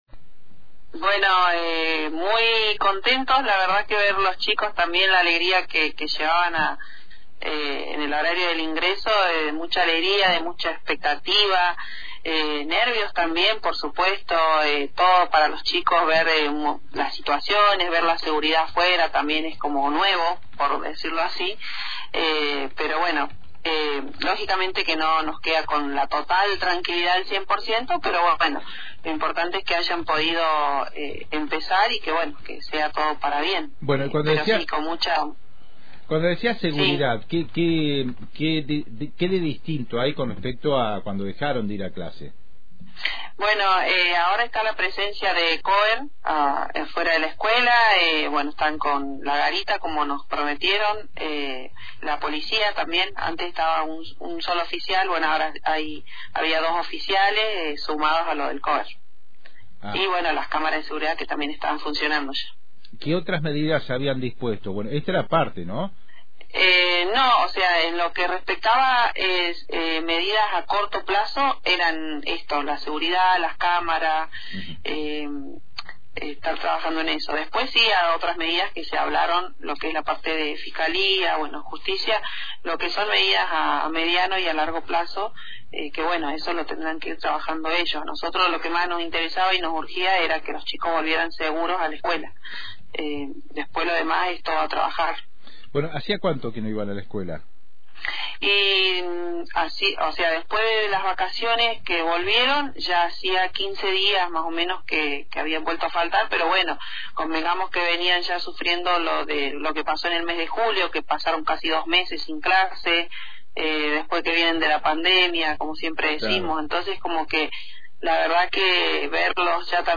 Esuchá la nota con una de las madres de estudiantes de la Escuela 357